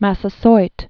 (măsə-soit) 1580?-1661.